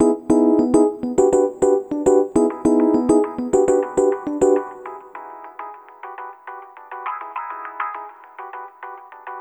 Ala Brzl 1 Fnky Piano-C#.wav